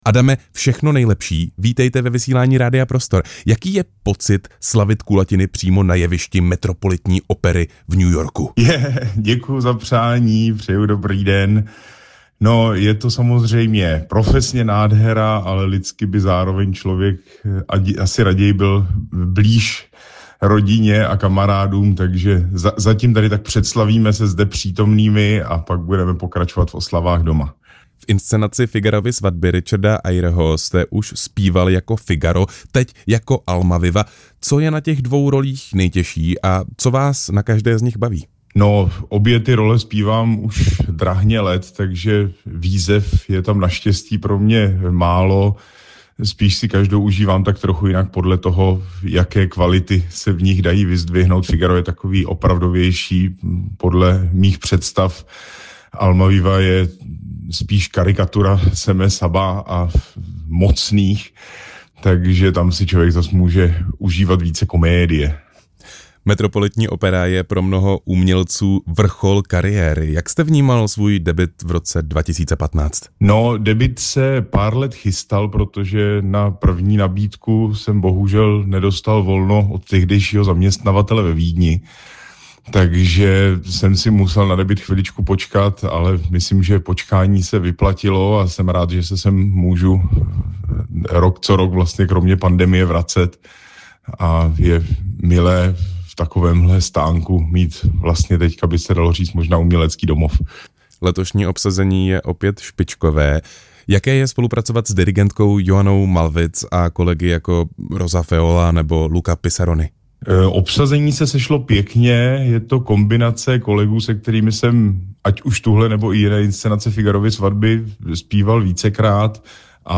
Rozhovor s basbarytonistou Adamem Plachetkou